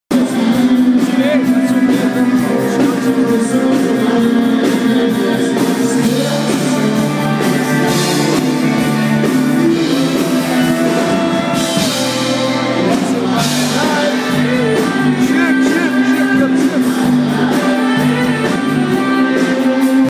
em Ribeirão Preto
voz e guitarra
baixo
bateria
saxofone, flauta e teclado
vocais